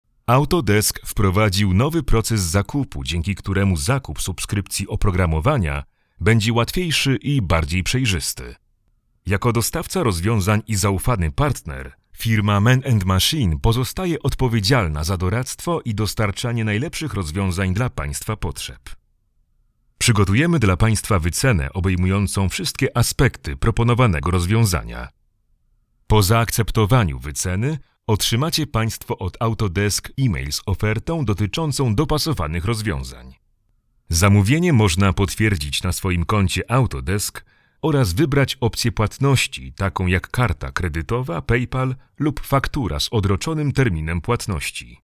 Diep, Toegankelijk, Veelzijdig, Vertrouwd, Commercieel
Explainer